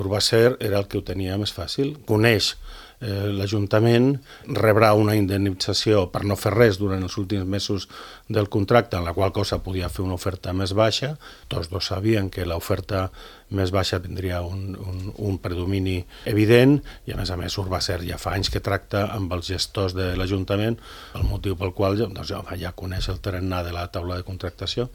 Ho ha exposat el portaveu dels comuns, Sebastián Tejada, a l’ENTREVISTA POLÍTICA de Ràdio Calella TV.